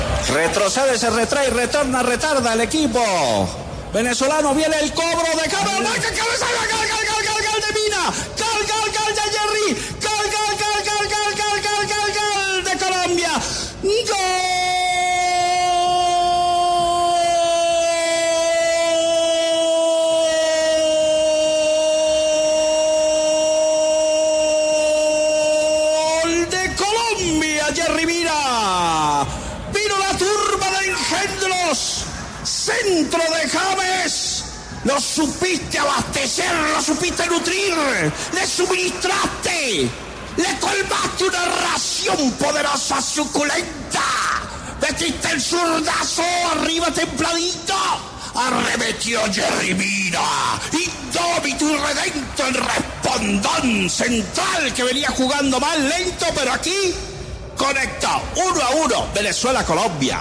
“Una ración poderosa suculenta”: Así fue la narración de Martín de Francisco del gol de Colombia
Martín de Francisco y Hernán Peláez narran el partido por la última fecha de Eliminatorias entre Colombia y Venezuela.